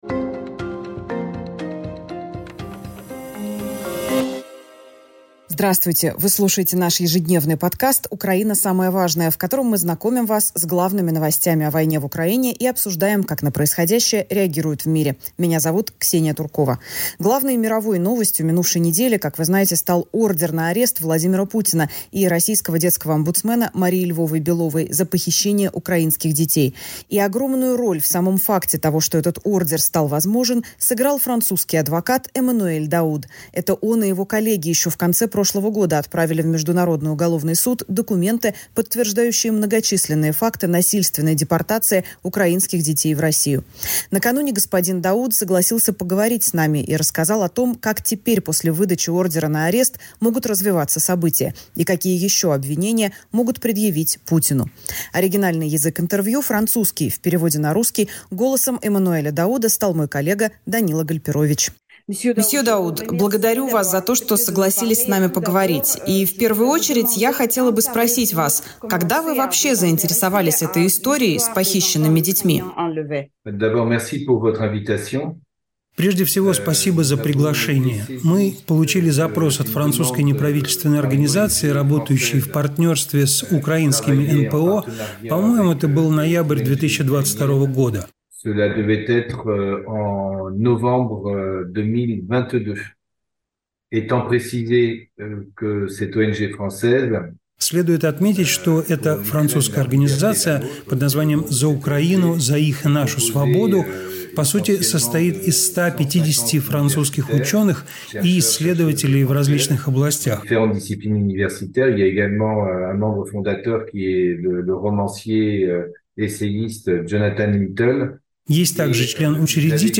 Как всегда по пятницам, подкаст выходит в формате интервью.